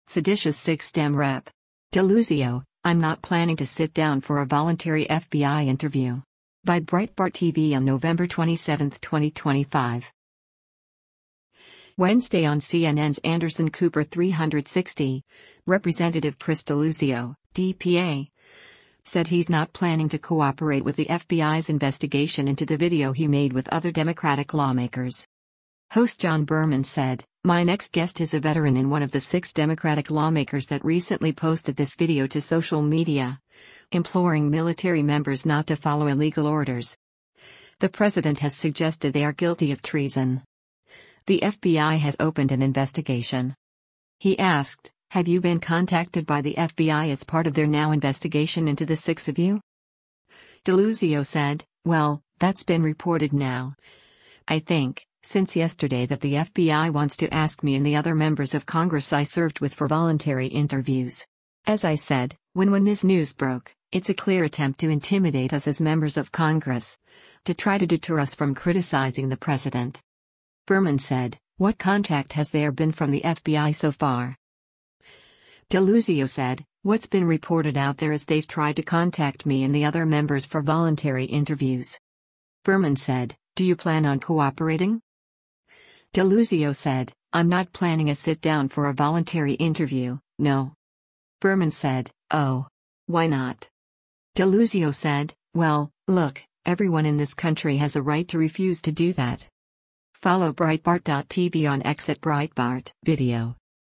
Wednesday on CNN’s “Anderson Cooper 360,” Rep. Chris Deluzio (D-PA) said he’s not planning to cooperate with the FBI’s investigation into the video he made with other Democratic lawmakers.